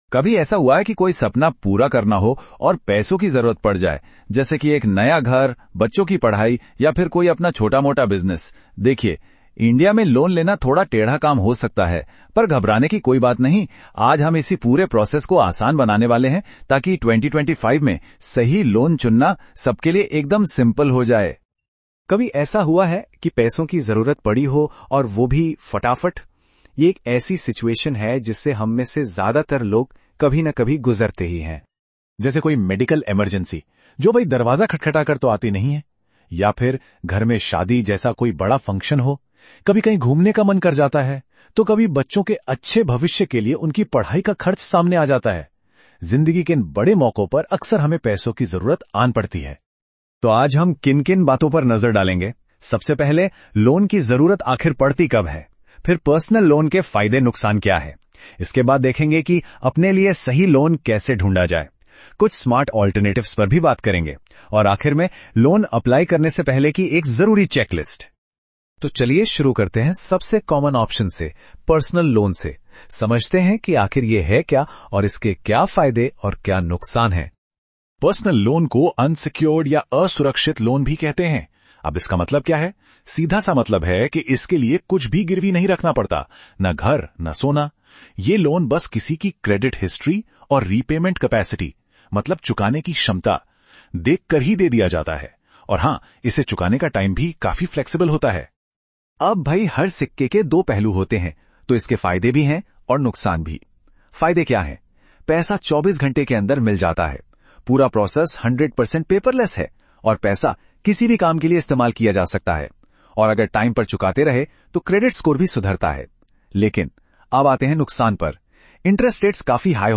Before you start reading, listen to a short Hindi audio overview of this post — it gives you a quick idea about the topic in just a minute.